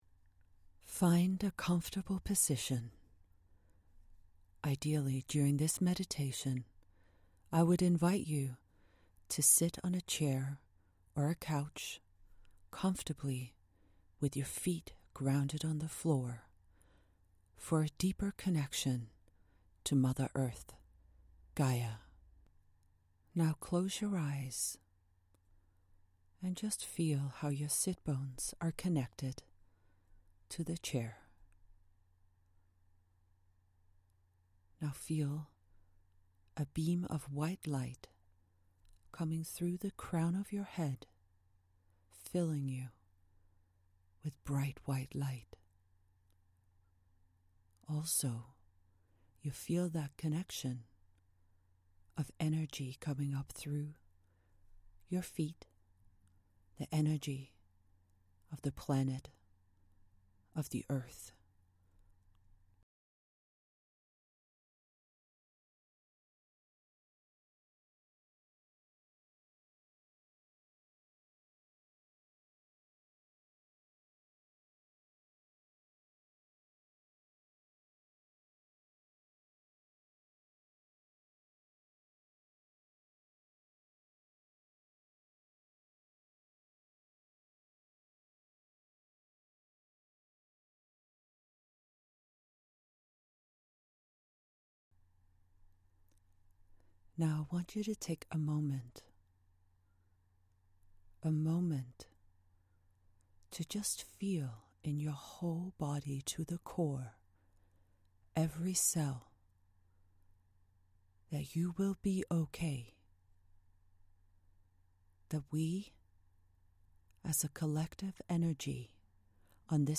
I made this meditation with the intention that the more we connect to a place of knowing that all will be OK the better we are equipped to handle what is thrown at us.
This was recorded with love and if you feel drawn to it have a listen.